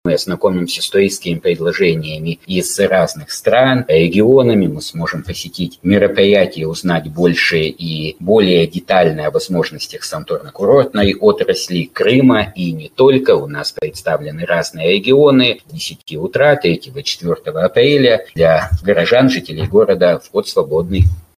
на пресс-конференции «Интерфакс».